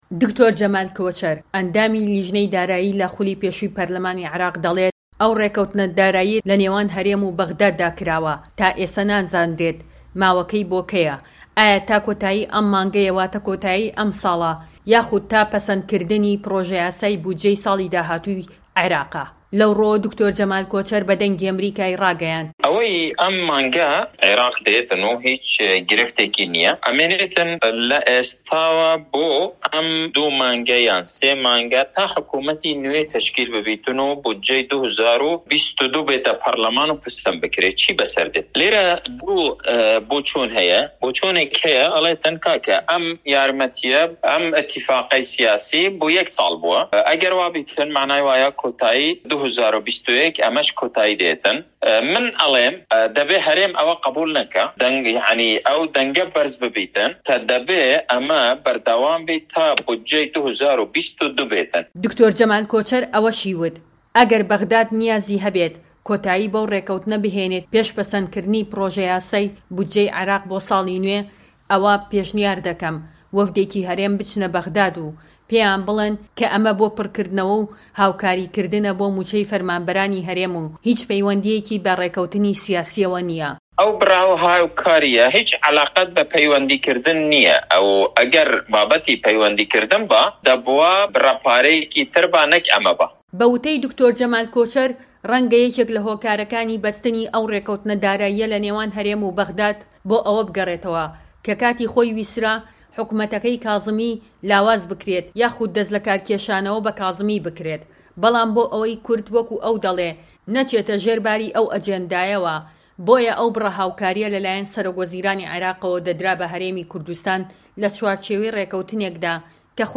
ڕاپۆرتێک لەو بارەیەوە Dec 14-021